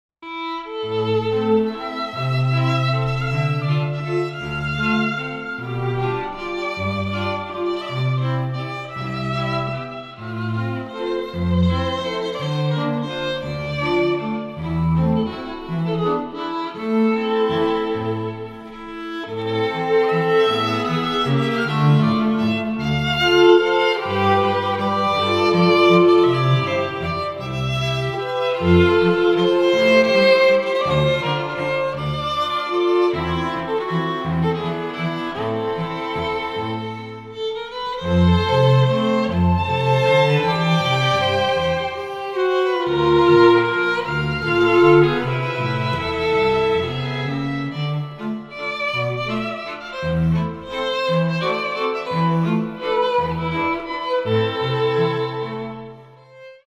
Репертуар дворца | Струнный квартет Нона